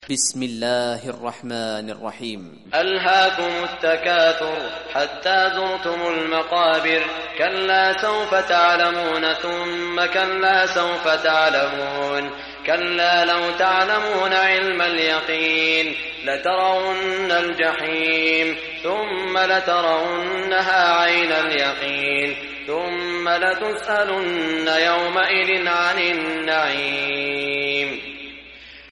Surah Takathur Recitation by Sheikh Shuraim
Surah Takathur, listen or play online mp3 quran recitation in the beautiful voice of Sheikh Saud Shuraim.